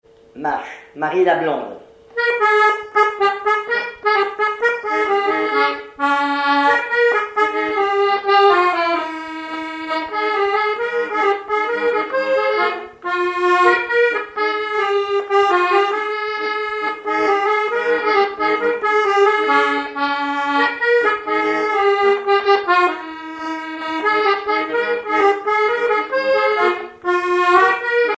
accordéon(s), accordéoniste
danse : marche
Répertoire à l'accordéon chromatique
Pièce musicale inédite